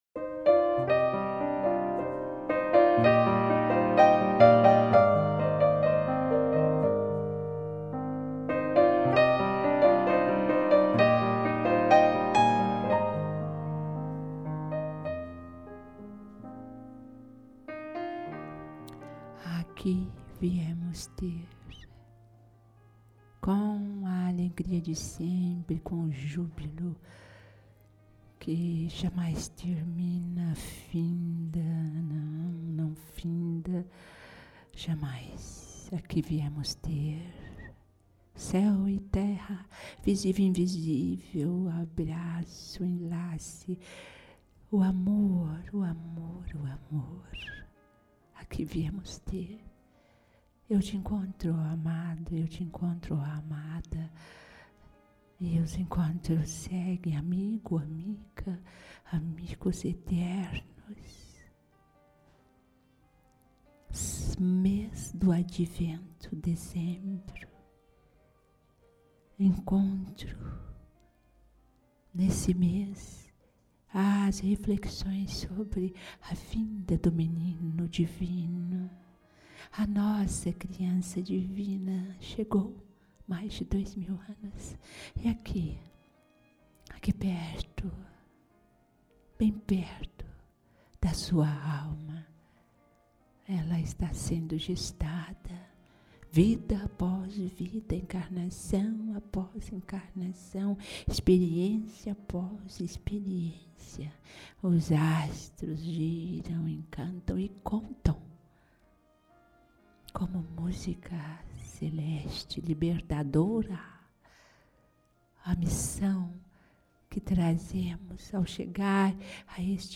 Mensagem espiritual pelo espírito Catarina de Senna, preposto de Eurípedes Barsanulfo
Psicofonía da médium